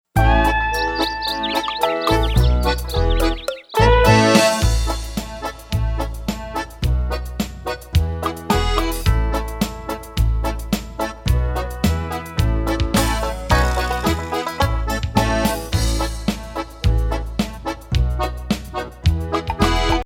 SOCIAL EMOTIONAL INSTRUMENTAL TRACKS